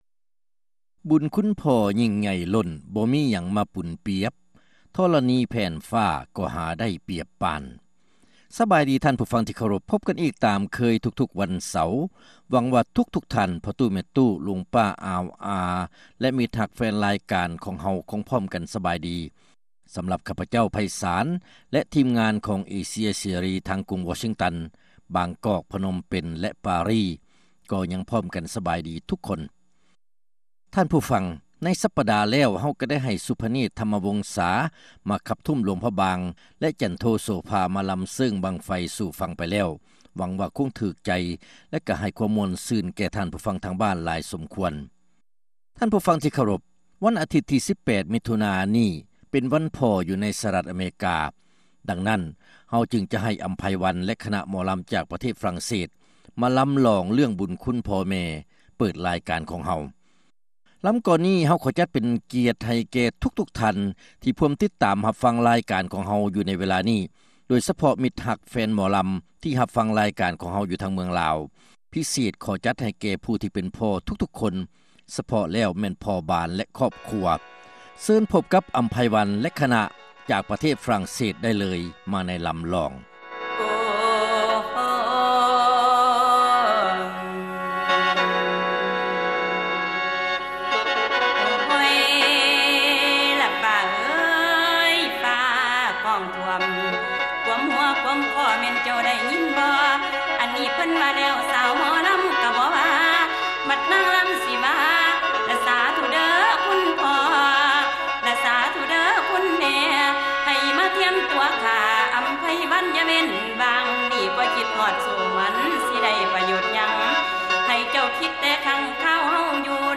ຣາຍການໜໍລຳ ປະຈຳສັປະດາ ວັນທີ 16 ເດືອນ ມິຖຸນາ ປີ 2006